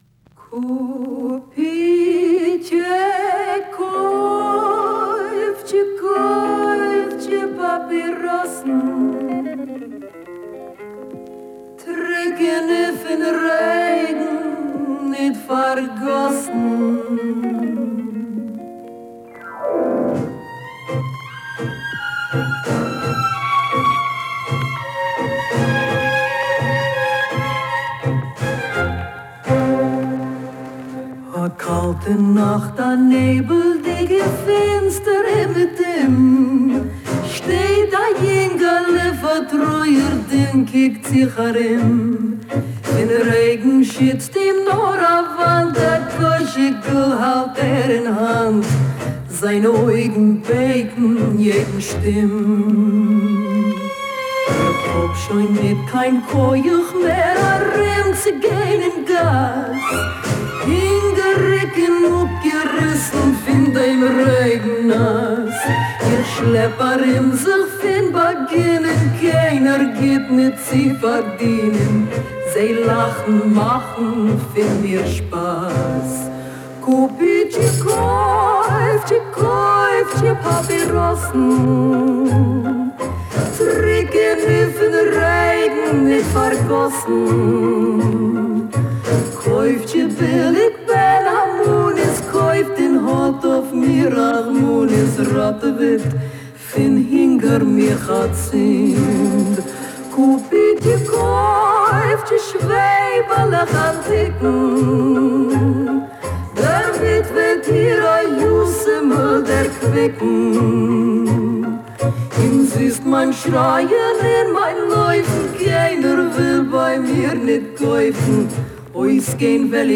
вокальном дуэте